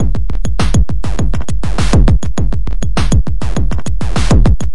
Synthesize
描述：Synthesizer with Organ. 2 bars. Sheet: G and A minor.
标签： 101bpm Loop Sample Synthesizer FX
声道立体声